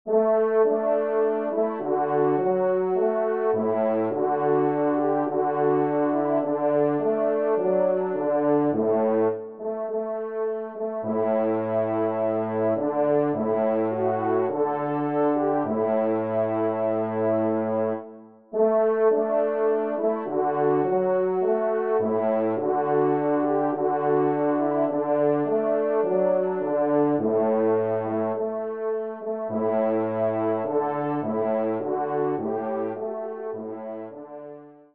Genre :  Divertissement pour Trompes ou Cors
3ème Trompe